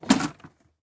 shutteropening.ogg